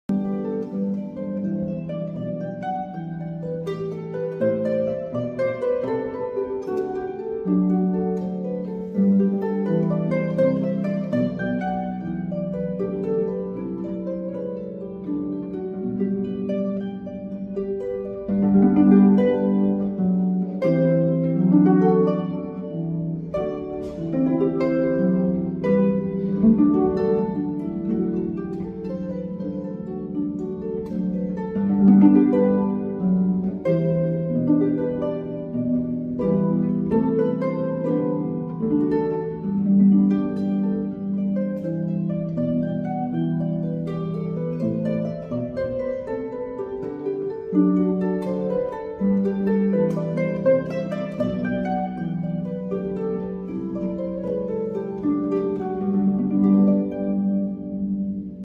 choral from Advent cantata
Harp music for Christmas